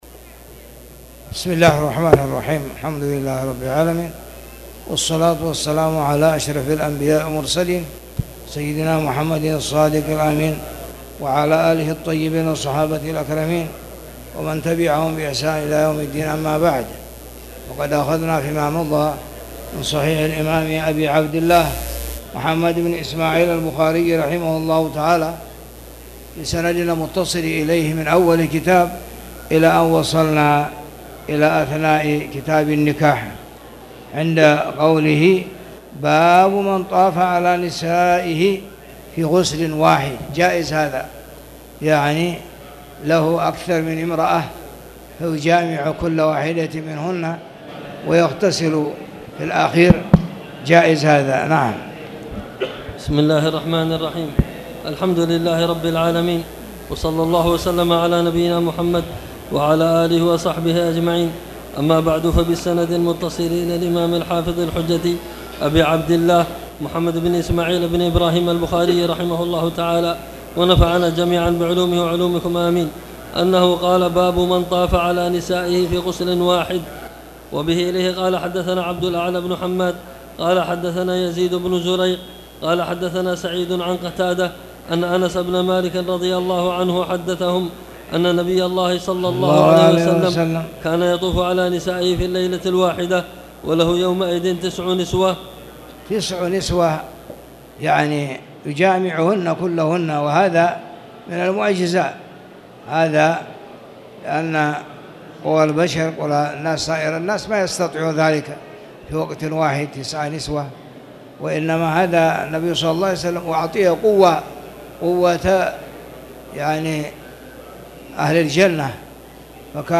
تاريخ النشر ١٦ ذو الحجة ١٤٣٨ هـ المكان: المسجد الحرام الشيخ